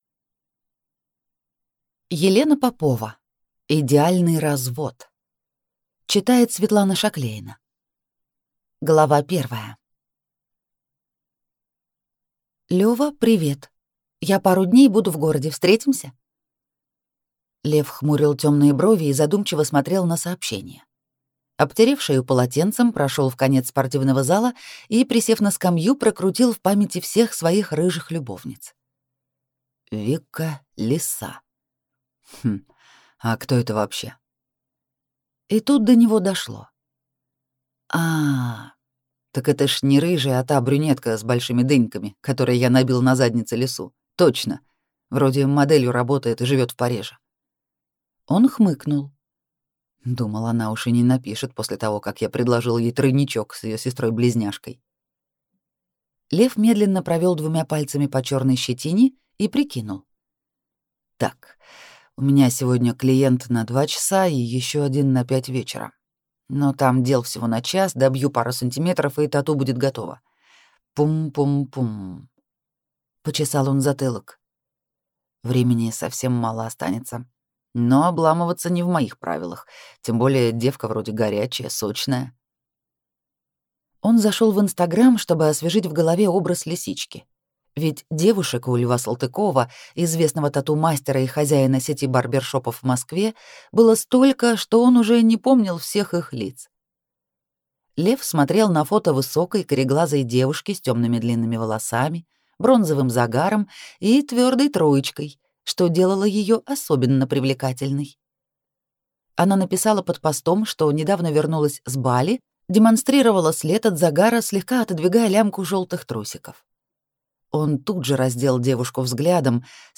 Аудиокнига Идеальный развод | Библиотека аудиокниг